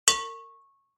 دانلود آهنگ تصادف 27 از افکت صوتی حمل و نقل
دانلود صدای تصادف 27 از ساعد نیوز با لینک مستقیم و کیفیت بالا